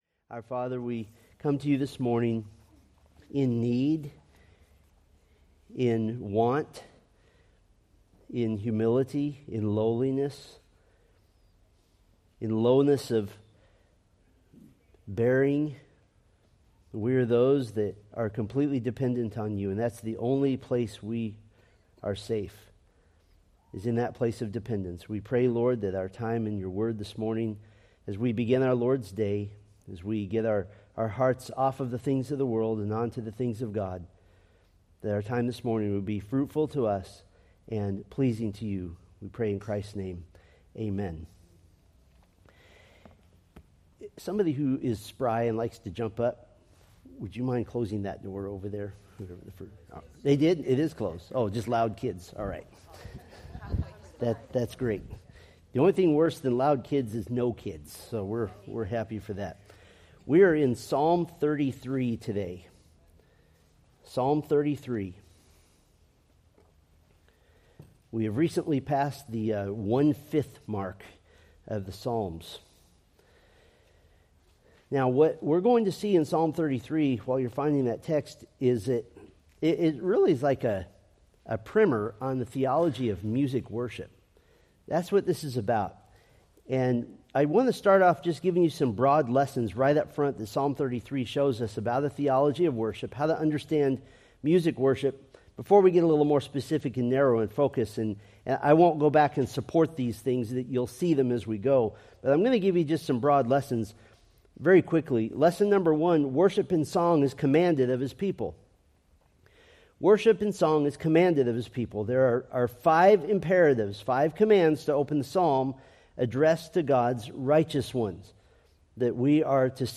Date: Dec 1, 2024 Series: Psalms Grouping: Sunday School (Adult) More: Download MP3